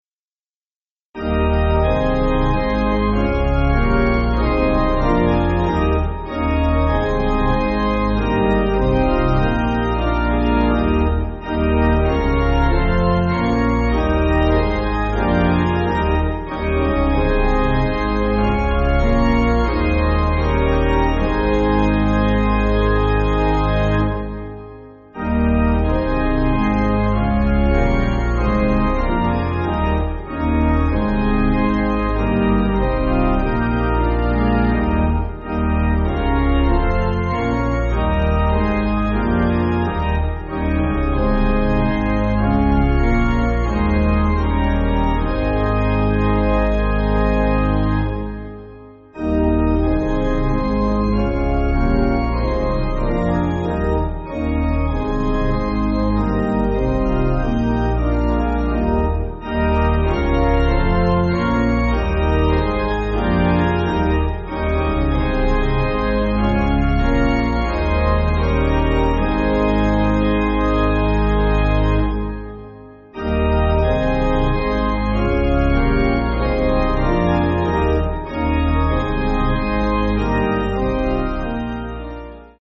(CM)   5/Ab